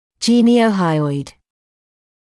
[ˌʤiːnɪəu’haɪɔɪd][ˌджиːниоу’хайойд]подбородочно-подъязычный